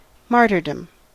Ääntäminen
IPA : /ˈmɑː(ɹ).tə(ɹ).dəm/